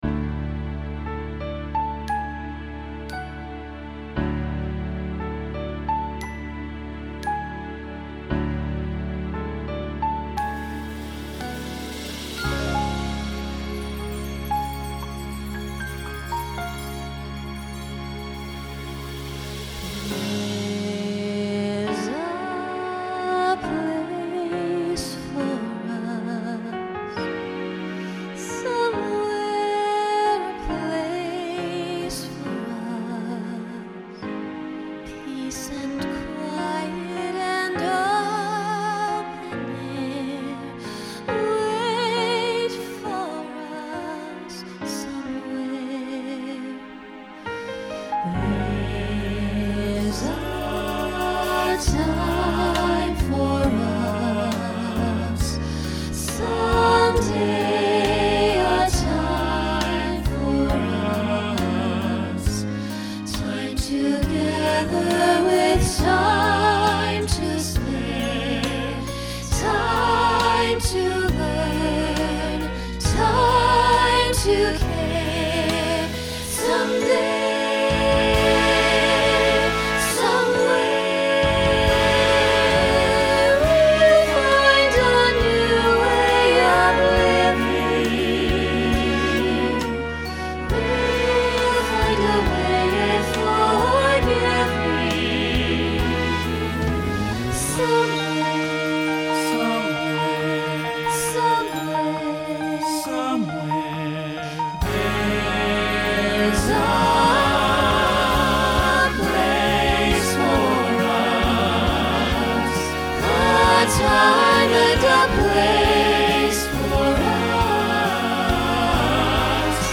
Genre Broadway/Film Instrumental combo
Ballad Voicing SATB